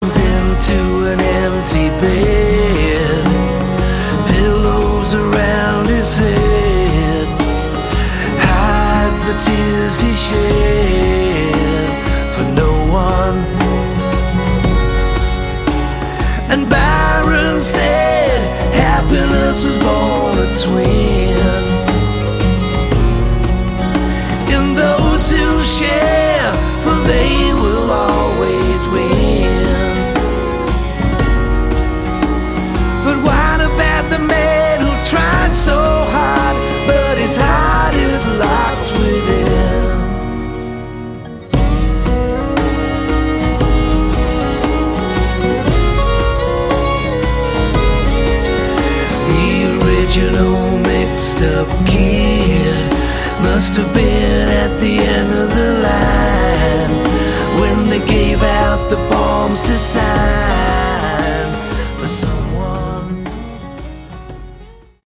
Category: Hard Rock
vocals, guitars, keyboards
drums
bass